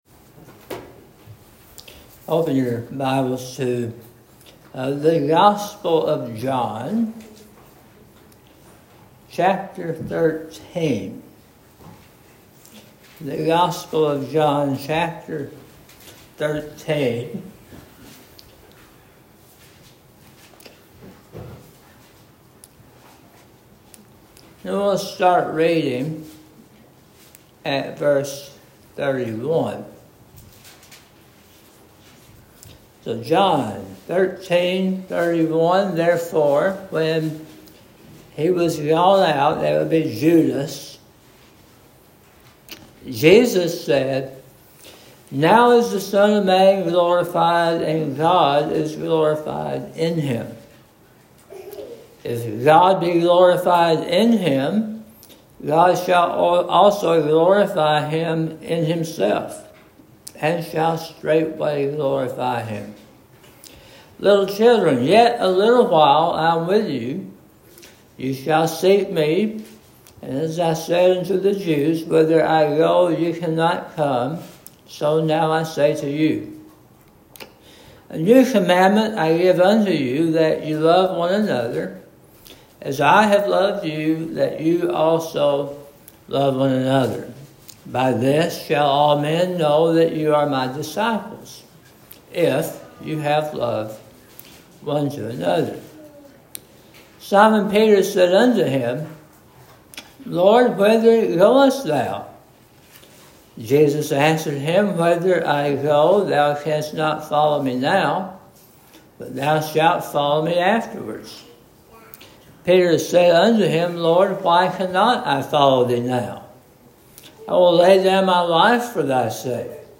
Sermon on John 13: 31-38
Preached at Mt. Nebo Cumberland Presbyterian Church, June 22, 2025. https